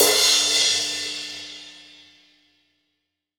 MG CRASH-4-S.WAV